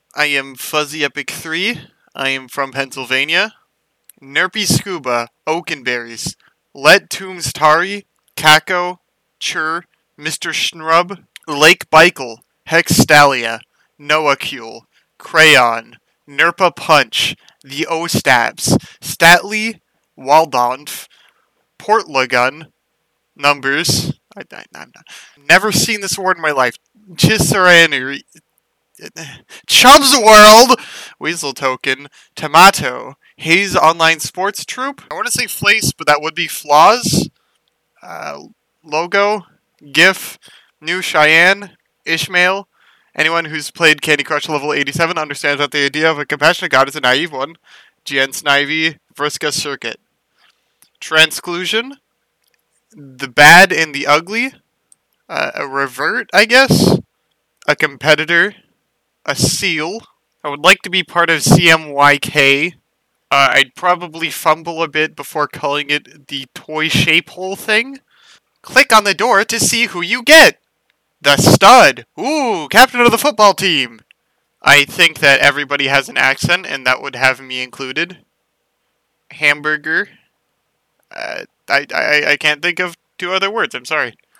Recordings of ourselves answering those questions.
my mic puffs a lot. sorry. i do not have a pop filter